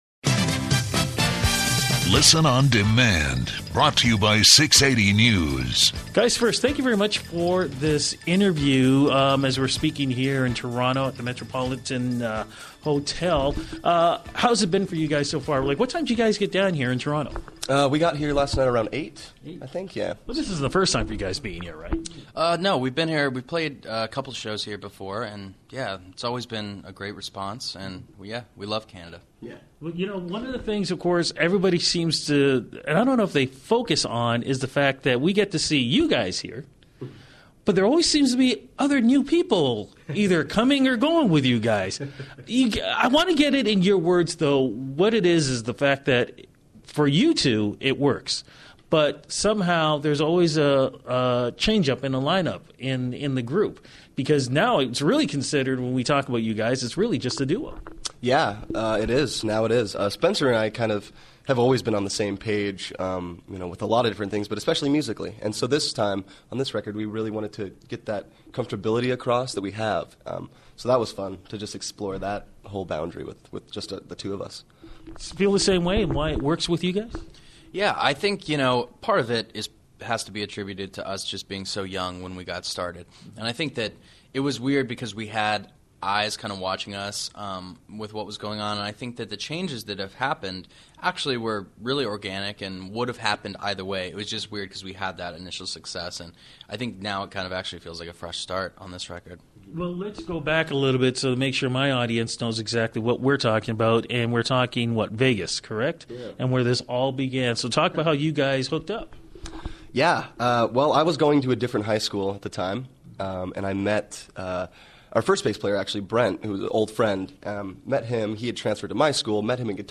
680news interj�